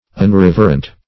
Unreverent \Un*rev"er*ent\, a.